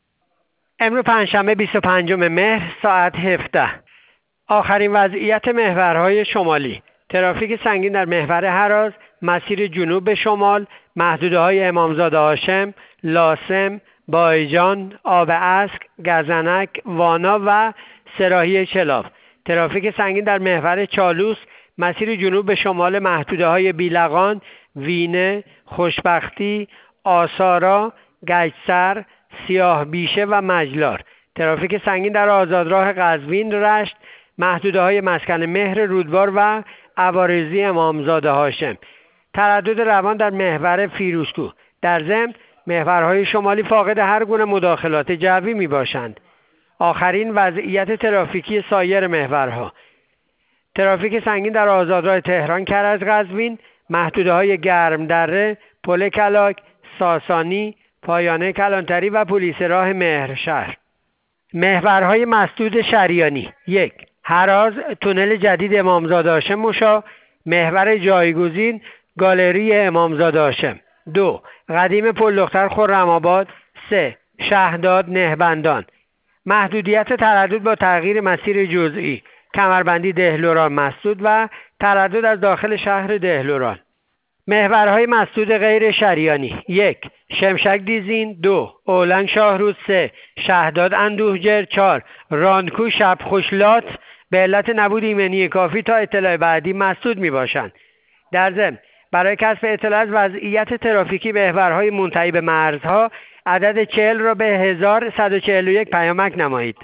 گزارش رادیو اینترنتی پایگاه خبری وزارت راه و شهرسازی از آخرین وضعیت ترافیکی جاده‌های کشور تا ساعت ۱۷ پنجشنبه ۲۵ مهر/ ترافیک سنگین در محورهای هراز و چالوس و آزادراه‌های قزوین-رشت و تهران- کرج-قزوین